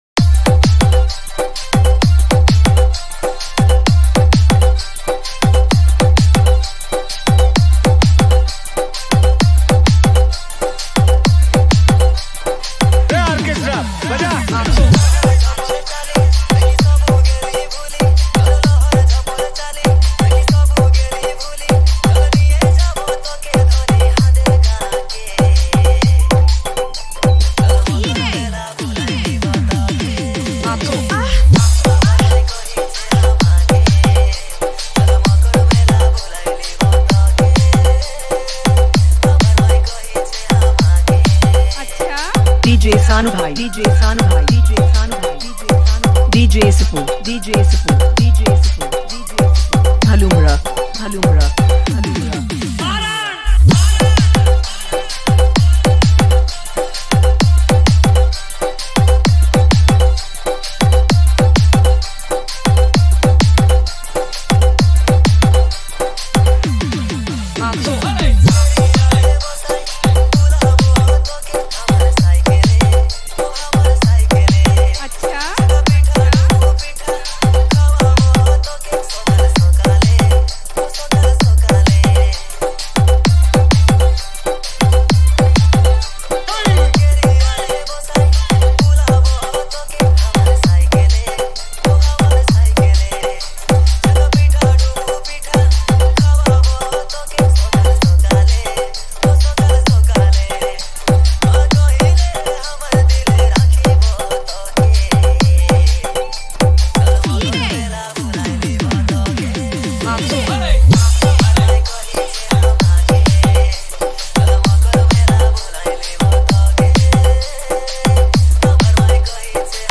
Category:  Sambalpuri Dj Song 2025
Jhumar Dj New